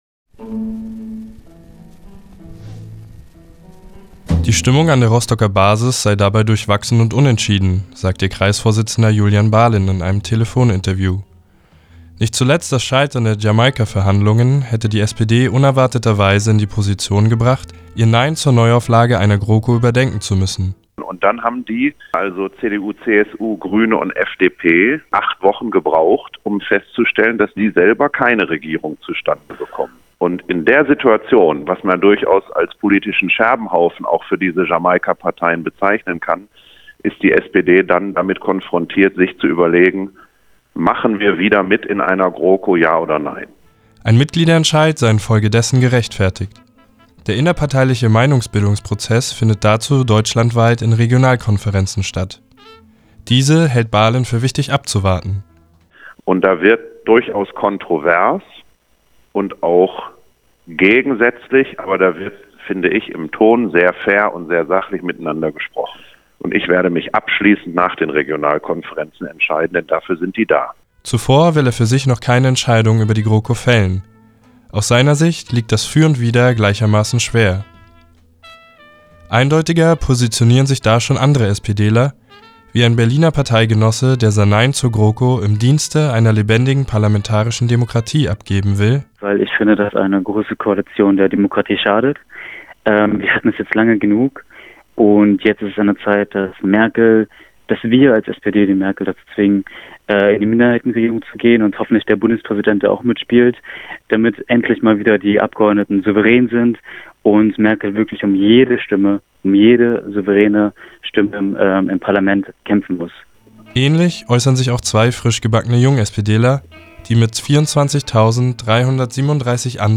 telefonisch kontaktiert